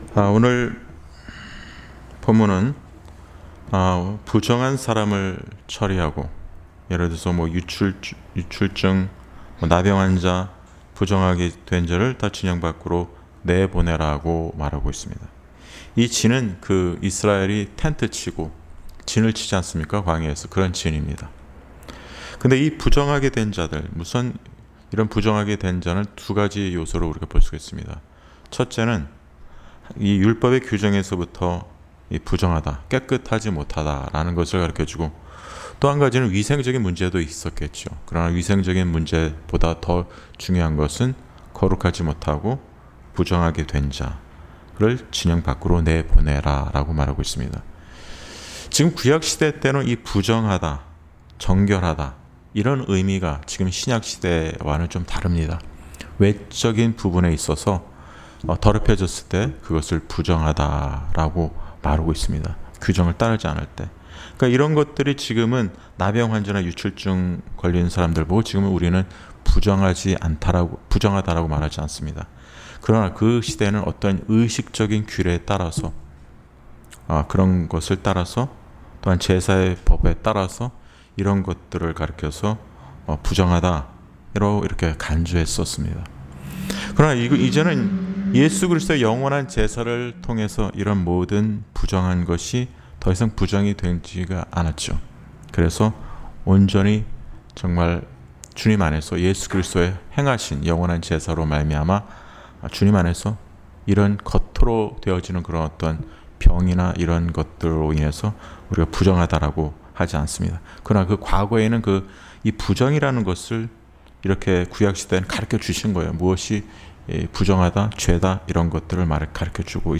Series: 금요기도회